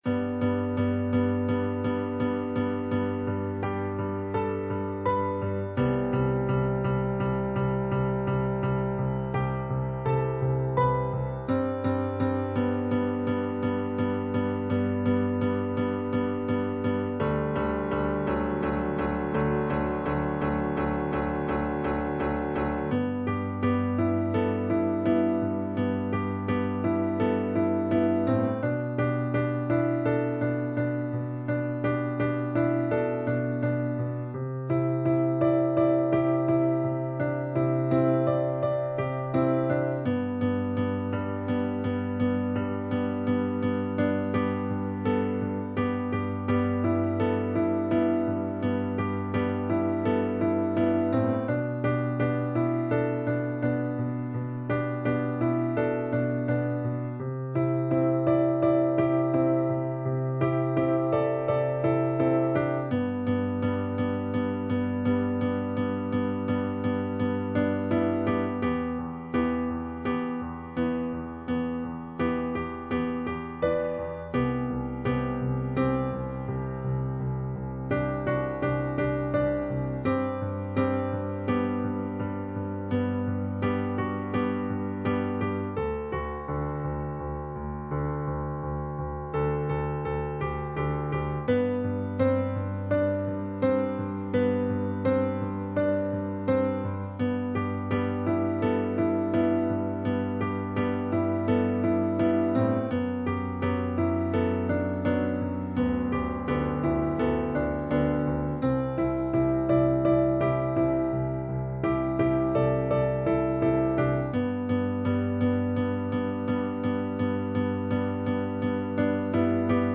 Piano Solo.